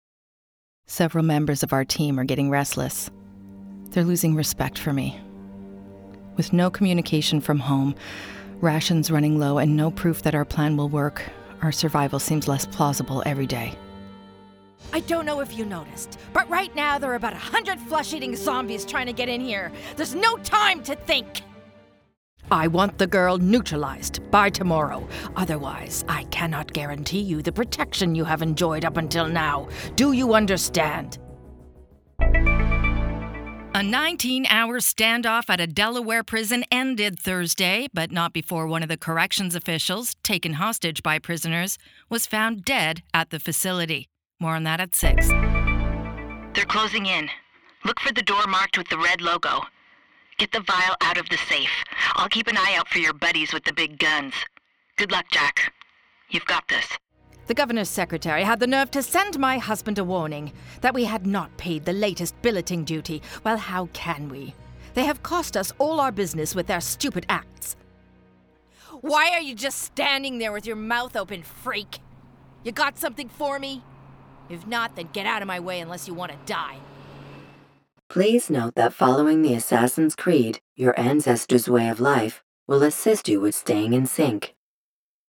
Video Games - EN